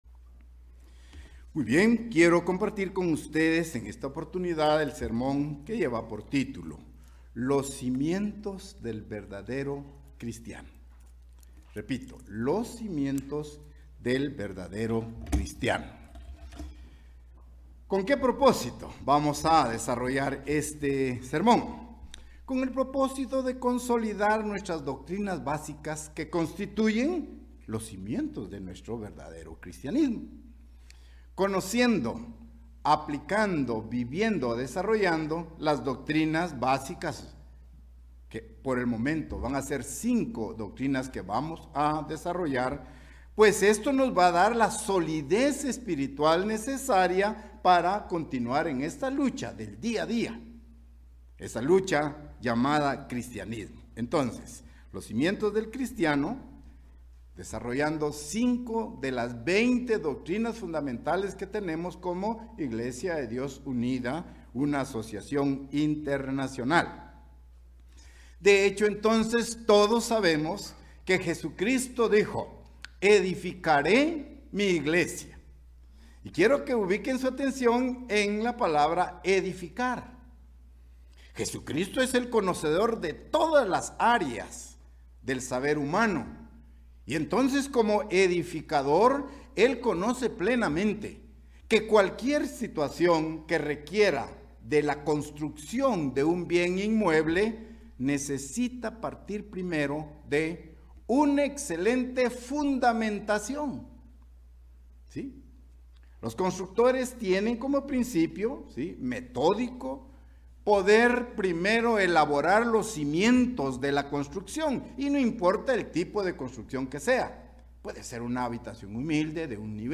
Mensaje entregado el 31 de diciembre de 2022.